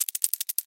Бег паука